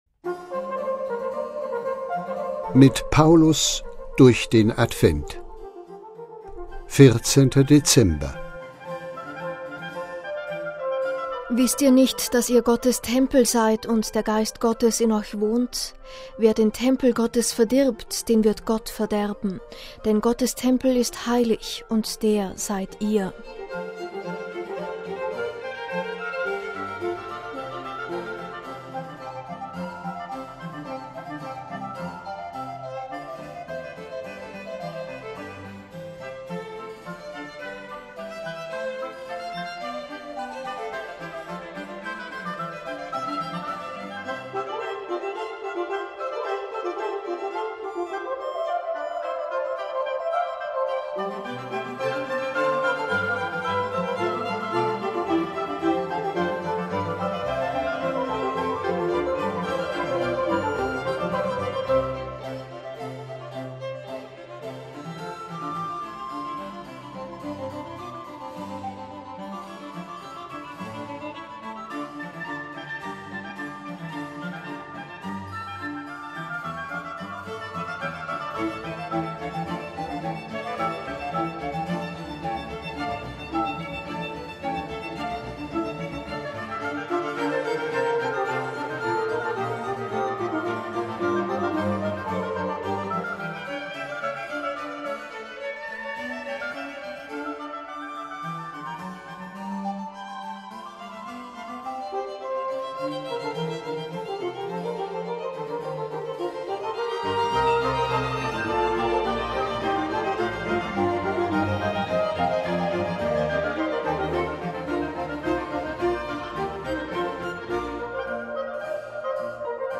„Mit Paulus durch den Advent“ ist das Motto dieses Audio-Adventskalenders, und an 24 Tagen lesen die Mitarbeiterinnen und Mitarbeiter einen ausgewählten Satz aus den Paulusbriefen.